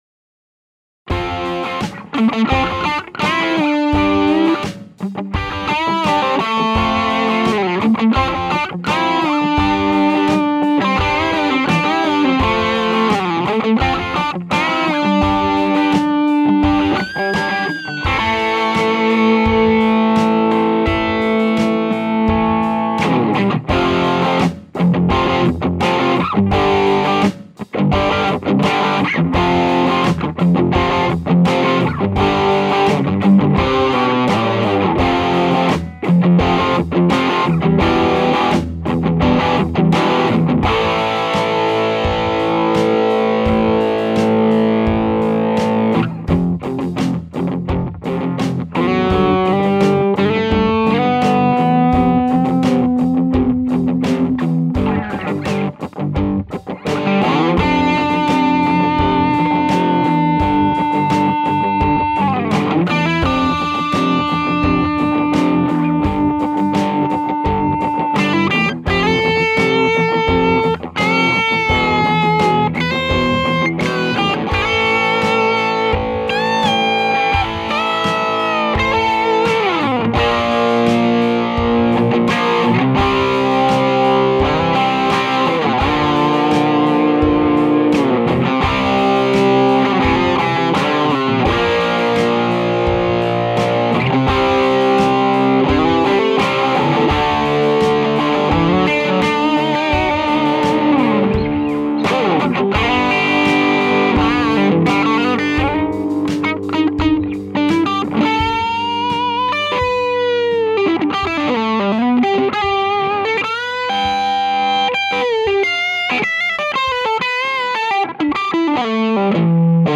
The channels were jumpered. The cab used was a open back 2x12 with one tone tubby papercone and one cannabis rex.
I included a bit of blues noodling at the end of the track that shows this trait nicely.
P.S. No FX on the guitars either.
Lots of beautiful harmonics in there.